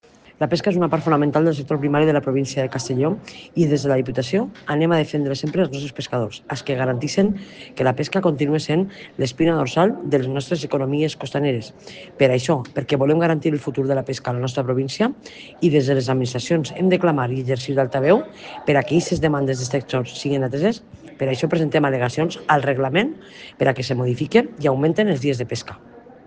Presidenta-Marta-Barrachina-alegaciones-pesca.mp3